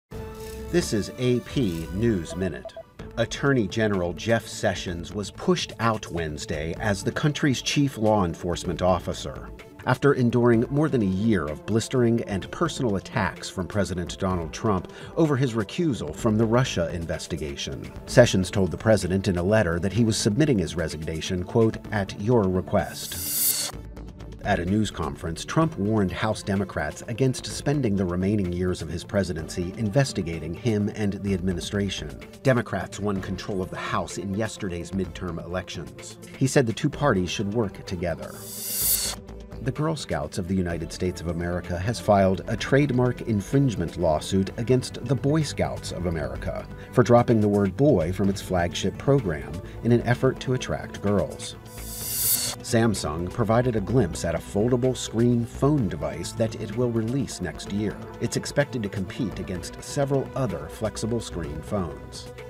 News